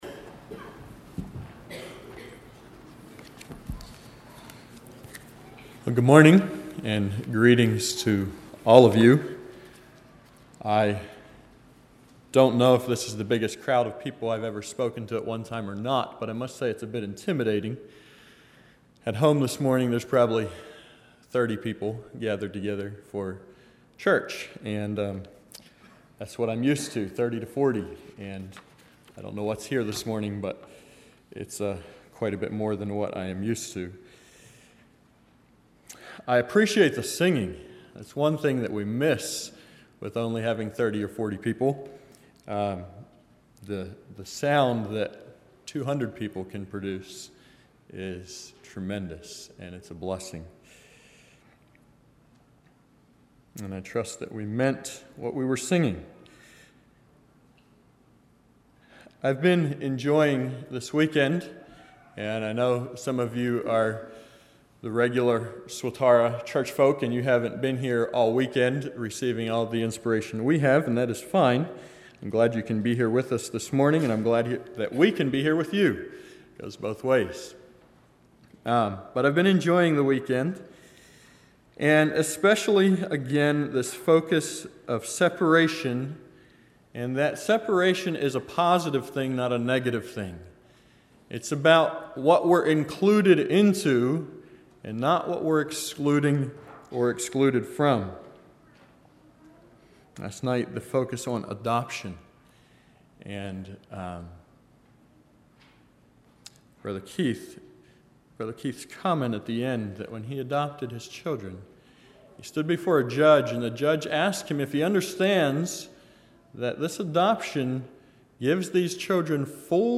40:00 Summary: Sunday Morning Topic at Youth Institute. Brotherhood Aid is the local body of believers lifting freewill offerings to cover financial needs within their midst.